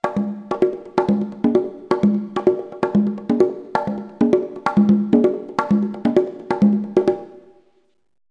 1 channel
conga.mp3